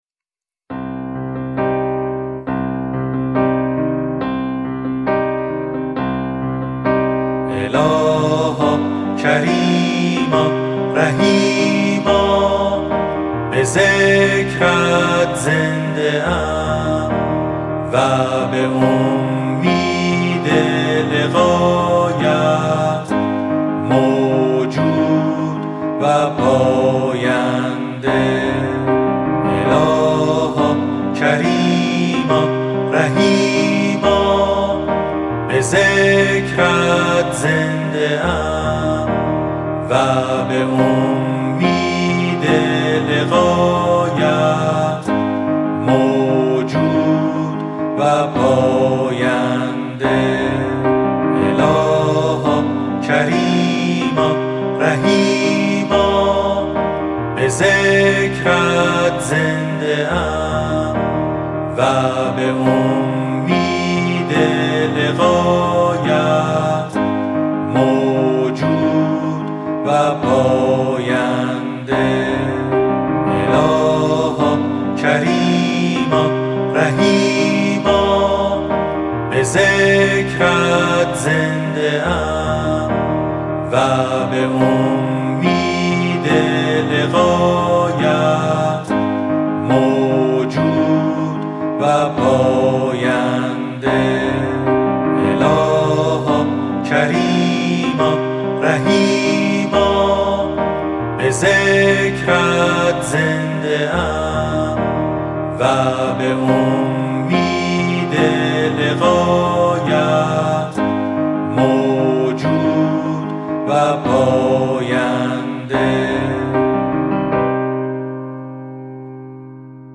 ذکر - شماره 14 | تعالیم و عقاید آئین بهائی
Download Track14.mp3 سایر دسته بندیها اذکار فارسی (آوازهای خوش جانان) 11034 reads Add new comment Your name Subject دیدگاه * More information about text formats What code is in the image?